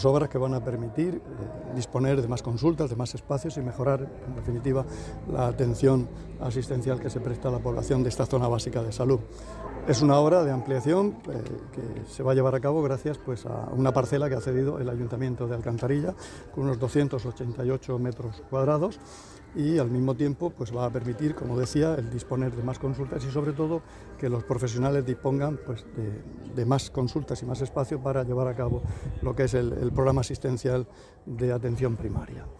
Declaraciones del consejero de Salud, Juan José Pedreño, sobre las obras de ampliación del centro de salud Alcantarilla-Sangonera